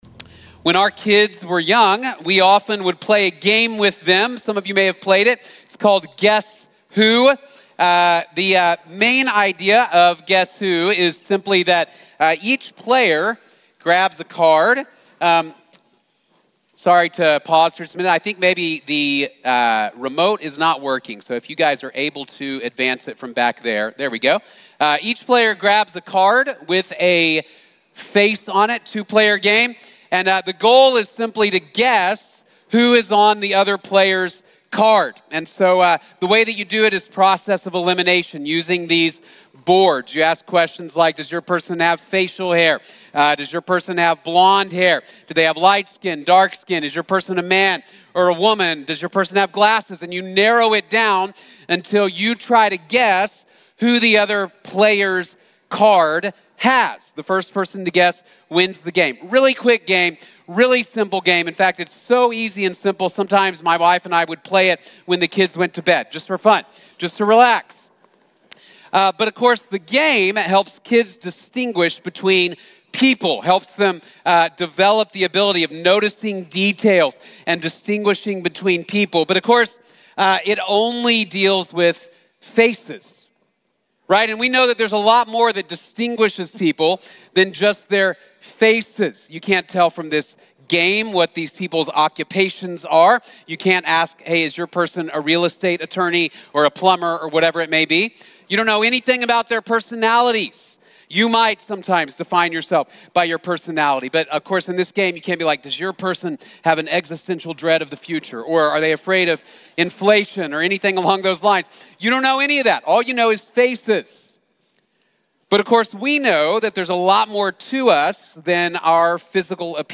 Made in God's Image | Sermon | Grace Bible Church